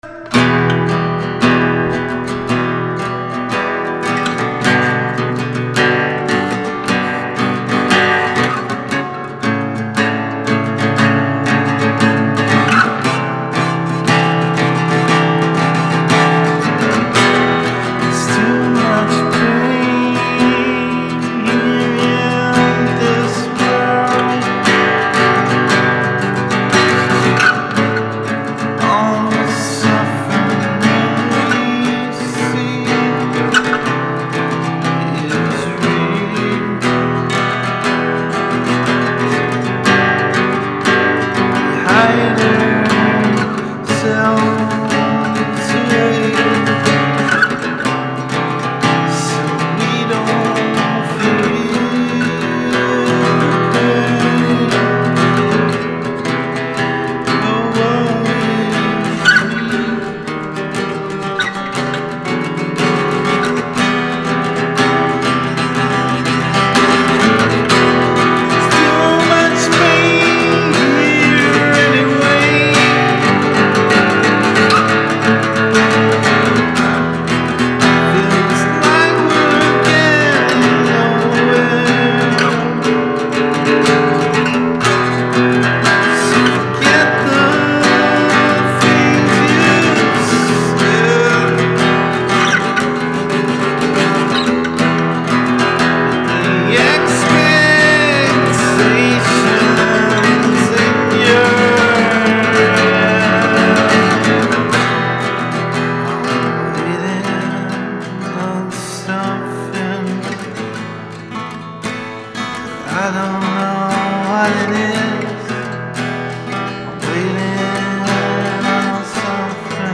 just another ram song
vocal guitar